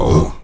AHOO E 2A.wav